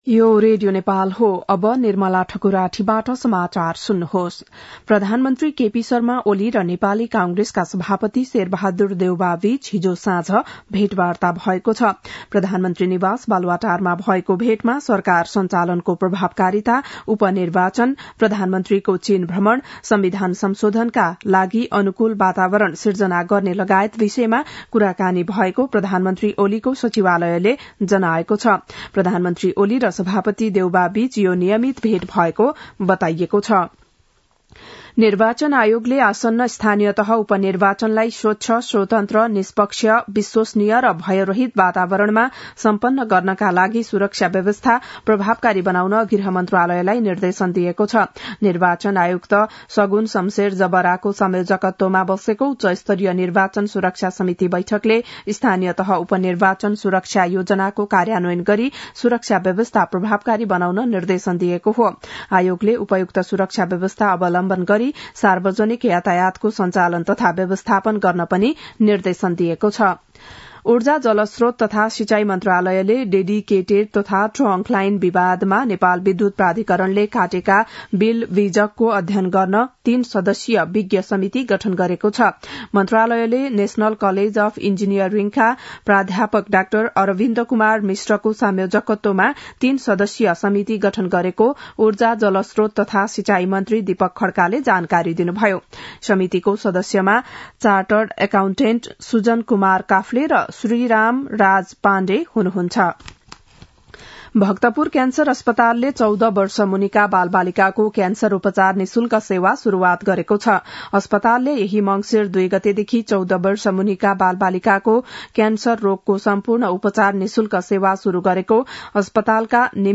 बिहान ११ बजेको नेपाली समाचार : ५ मंसिर , २०८१
11-am-nepali-news-1-4.mp3